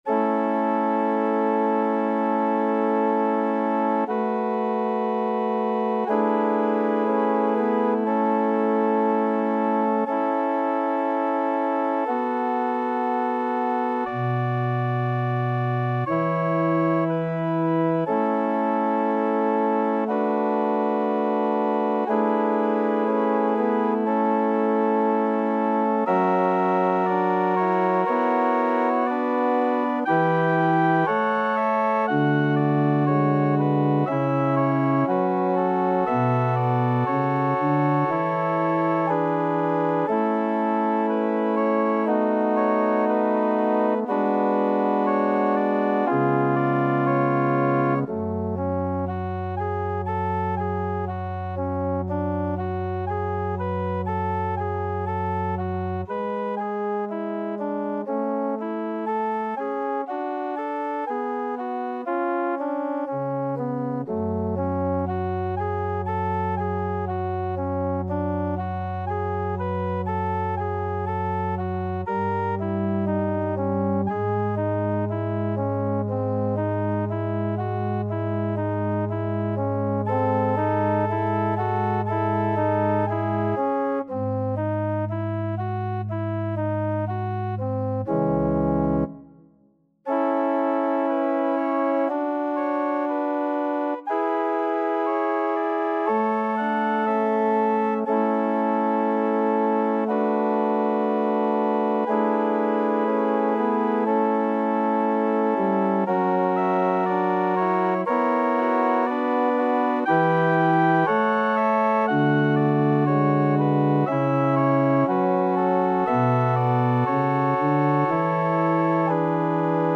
Play (or use space bar on your keyboard) Pause Music Playalong - Piano Accompaniment Playalong Band Accompaniment not yet available reset tempo print settings full screen
A major (Sounding Pitch) (View more A major Music for Viola )
~ = 100 Andantino sempre legato =60 (View more music marked Andantino)
Classical (View more Classical Viola Music)